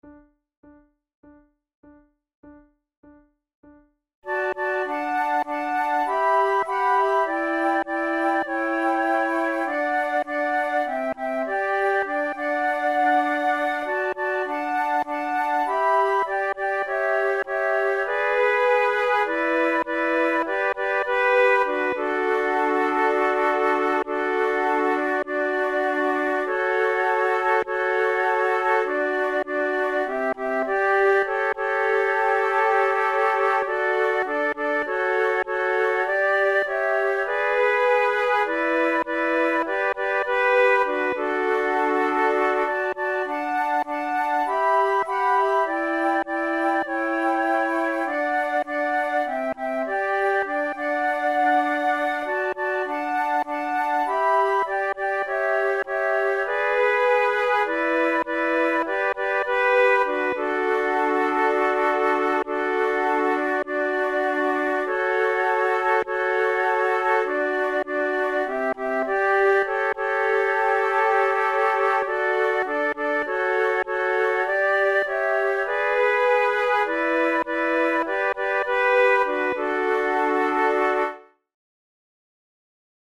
InstrumentationFlute quartet
KeyD major
Time signature4/4
Tempo100 BPM
Christmas carols, Spirituals, Traditional/Folk
African-Americal spiritual, arranged for four flutes